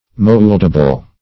Search Result for " mouldable" : The Collaborative International Dictionary of English v.0.48: Moldable \Mold"a*ble\, Mouldable \Mould"a*ble\, a. Capable of being molded or formed.
mouldable.mp3